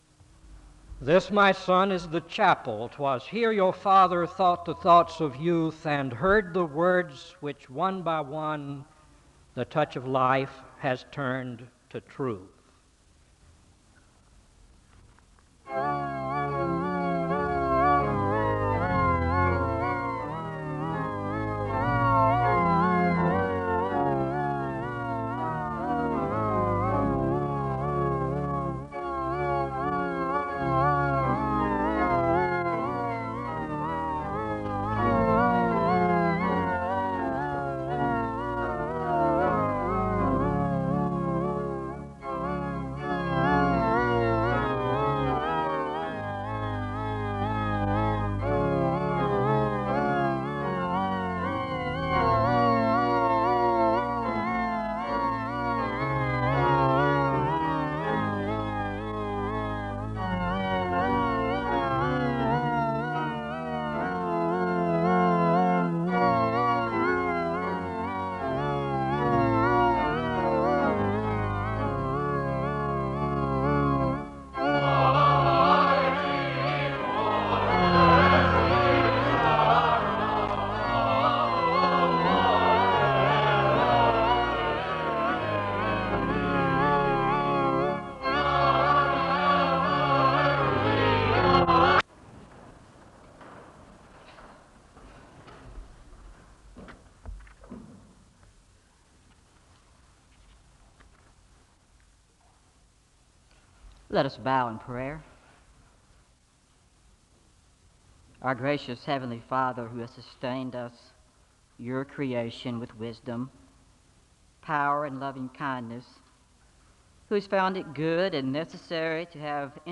SEBTS Chapel
The service begins with a liturgical reading, and the choir sings a song of worship (00:00-01:33). The speaker gives a word of prayer, and he reads Matthew 10:34-39 (01:34-04:53). The choir sings a song of worship (04:54-06:50).